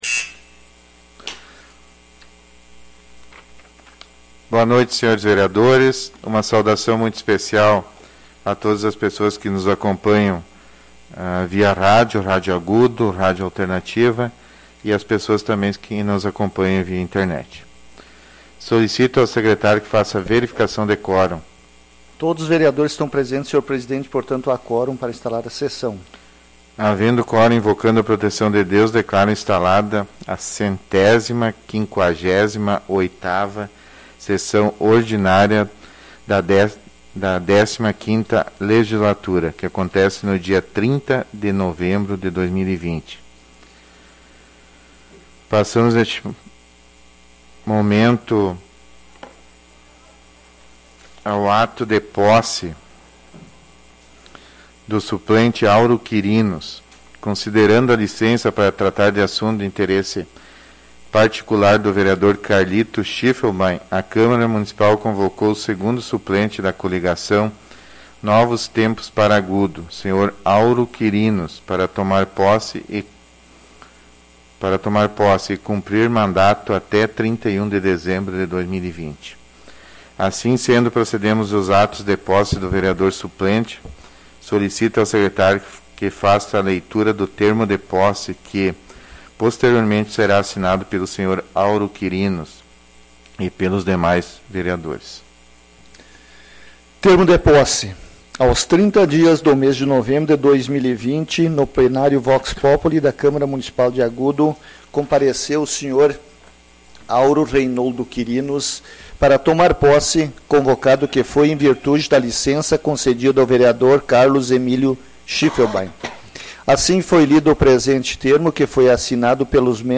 Áudio da 158ª Ordinária da 4ª Sessão Legislativa da 15ª Legislatura, de 30 de novembro de 2020